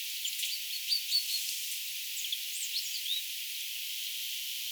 talitiaislintu?
ilmeisesti_talitiaislintu.mp3